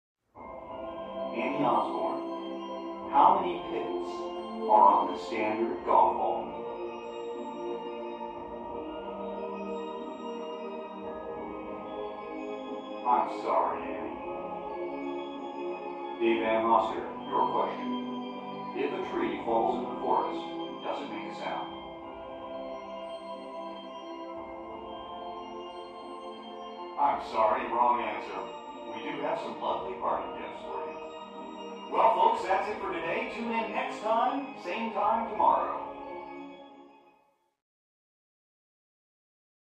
Television; Game Show Questions With Music And Announcer. From Next Room.